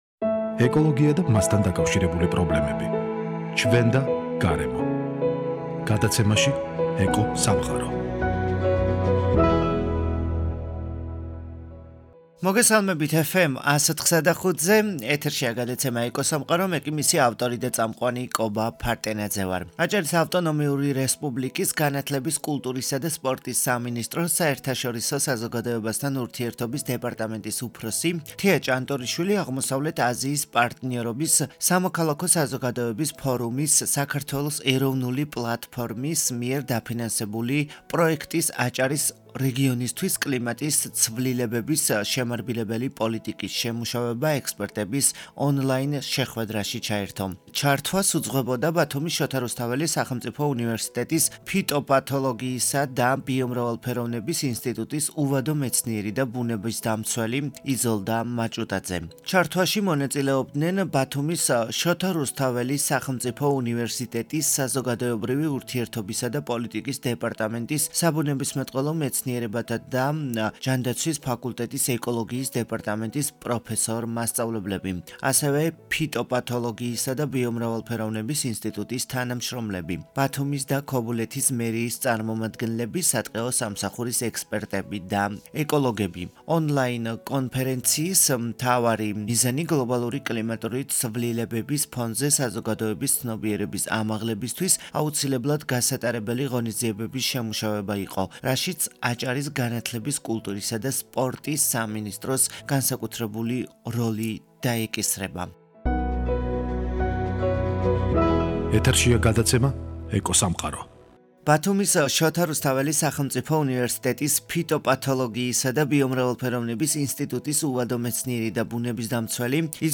სწორედ ის არის გადაცემის სტუმარი და  შეხვედრის ძირითად მიზანზე საუბრობს.